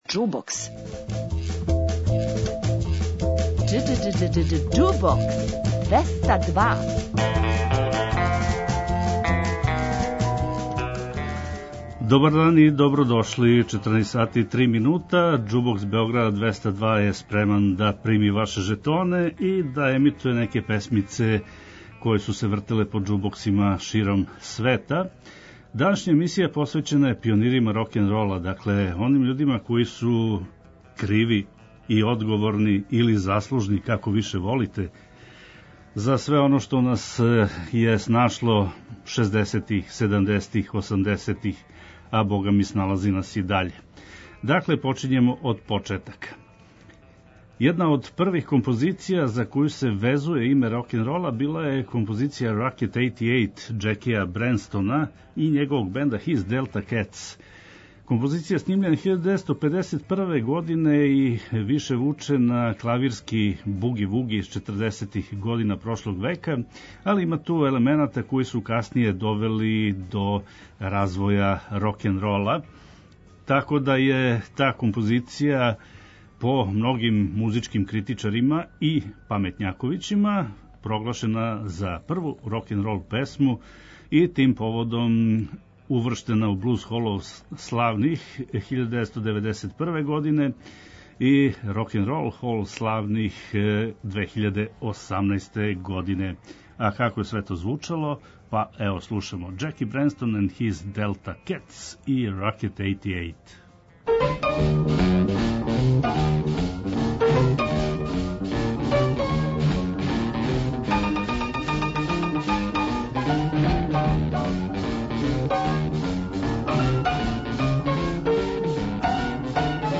Уживајте у пажљиво одабраној старој, страној и домаћој музици.